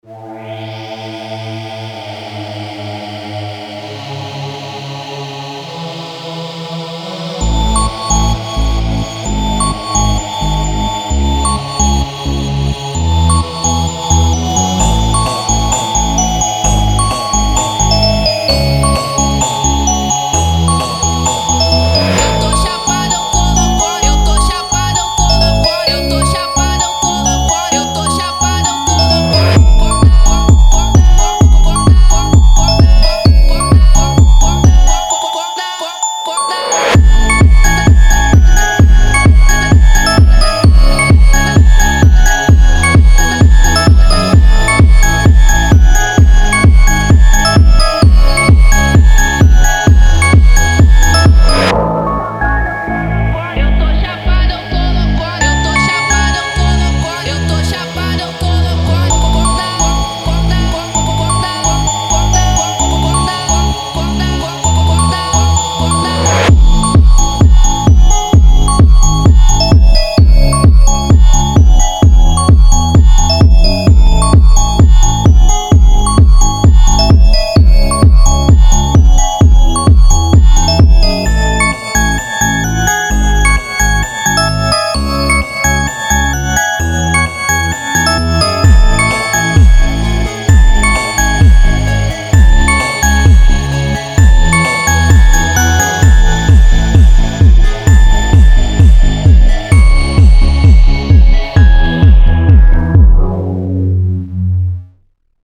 Трек размещён в разделе Зарубежная музыка / Фонк.